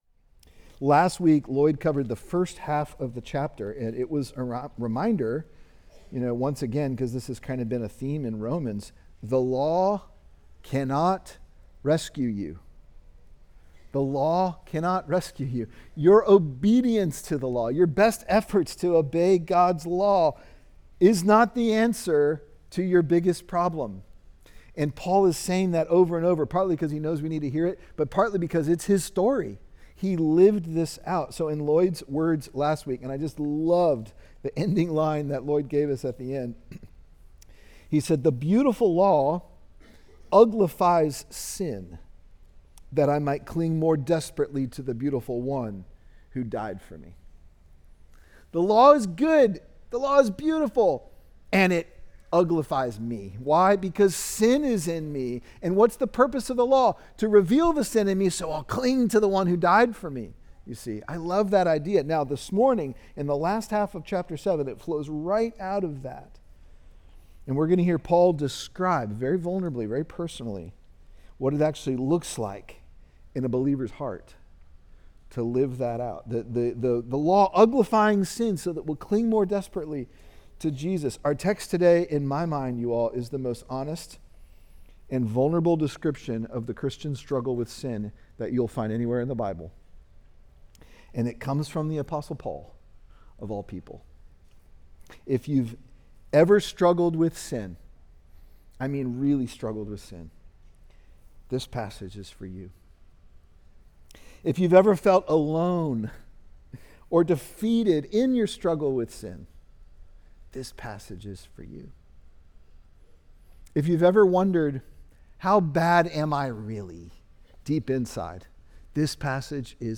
Sermon Romans: Righteousness Received